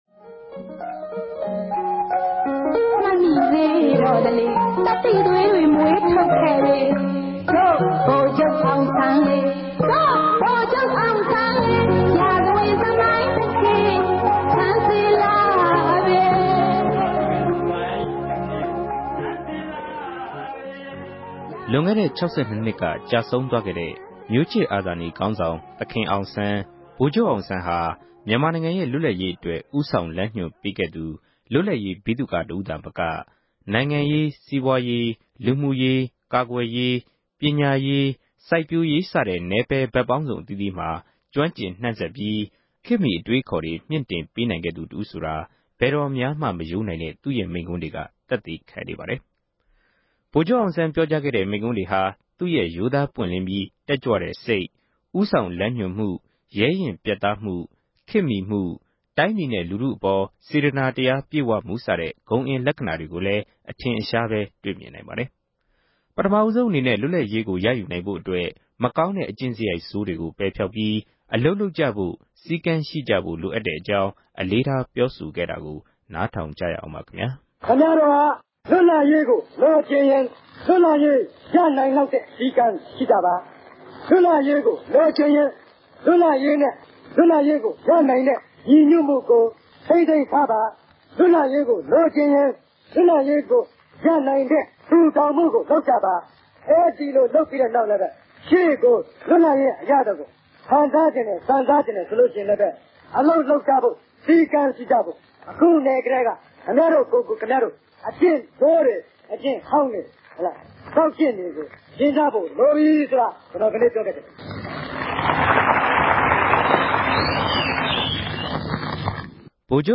ဗိုလ်ခဵြပ်အောင်ဆန်း၏ မိန်ႛခြန်းကောကိုံတ်ခဵက်။